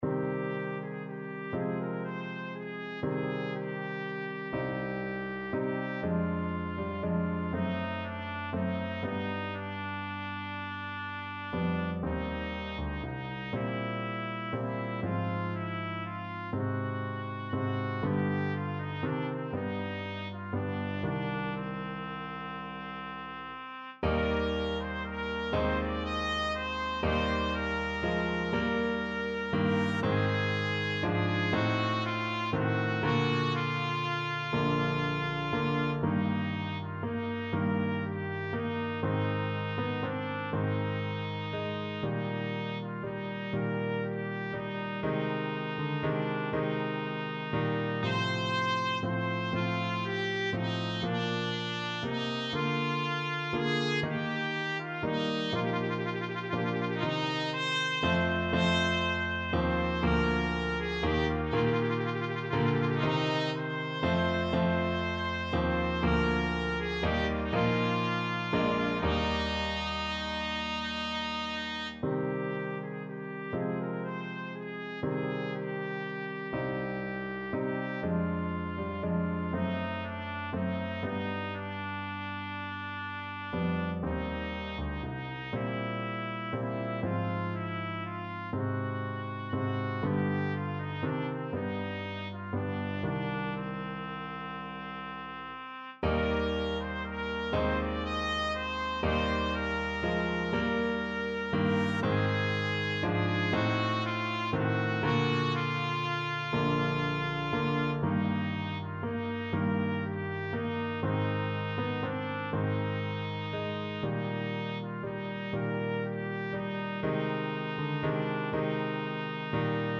Trumpet
12/8 (View more 12/8 Music)
G4-Eb6
C minor (Sounding Pitch) D minor (Trumpet in Bb) (View more C minor Music for Trumpet )
Classical (View more Classical Trumpet Music)
tartini_devil_trill_1st_mvt_TPT.mp3